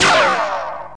assets/nx/nzportable/nzp/sounds/weapons/papfire.wav at edab9493ff78af0ab9f600fb9feef688971f992a